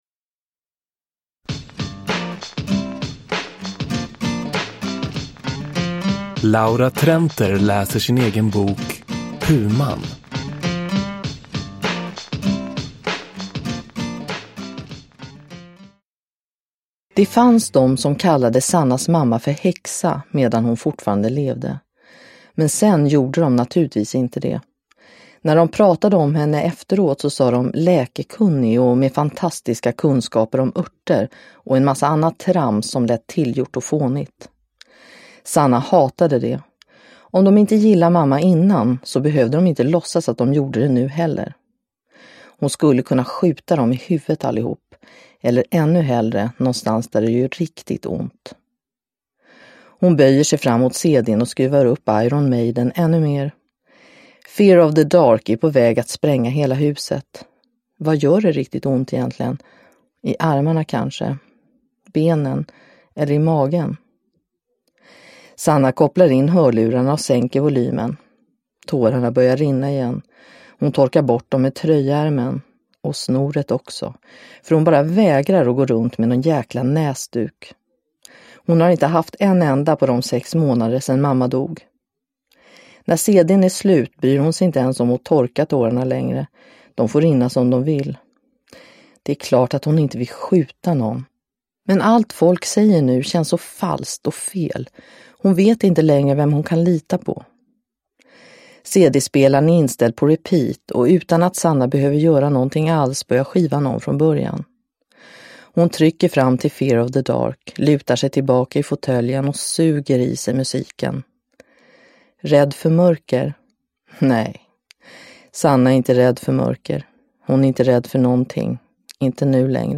Puman – Ljudbok – Laddas ner
Uppläsare: Laura Trenter